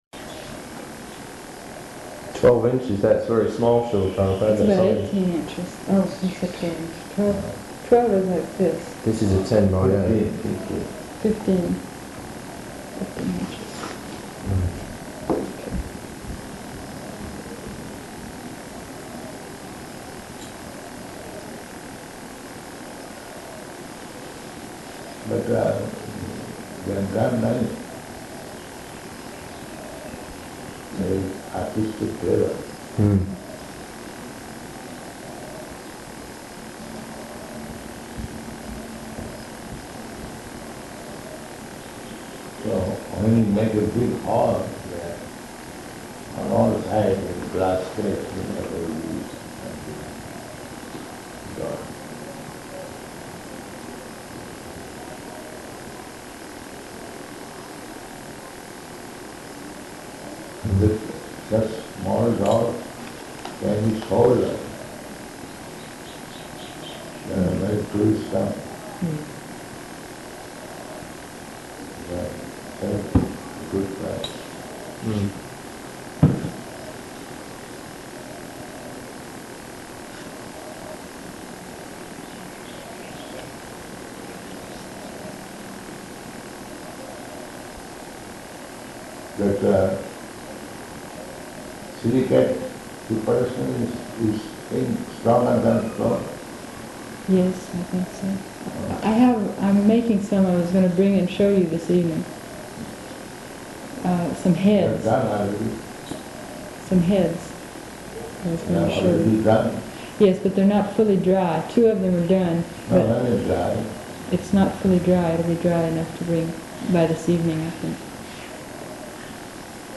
Room Conversation About Deity-Making and Tulasi Tea
-- Type: Conversation Dated: May 21st 1976 Location: Honolulu Audio file